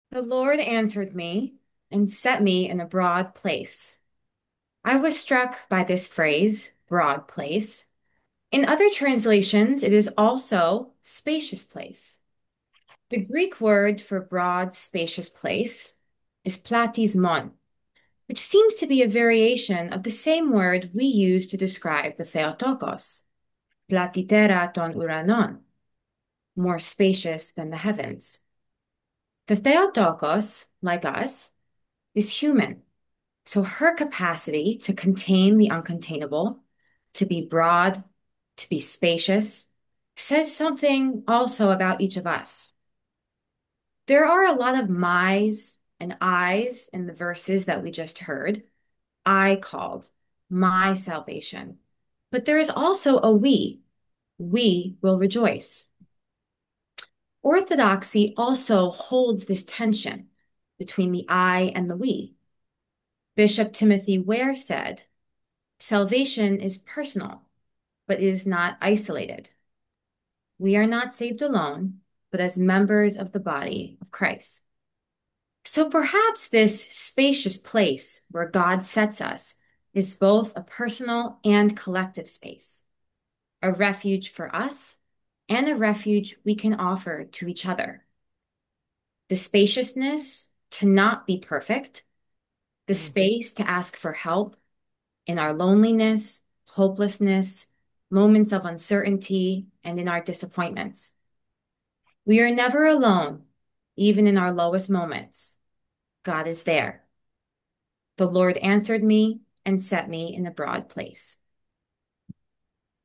New Year’s Thanksgiving Prayer Service & Fellowship Zoom Event Reflections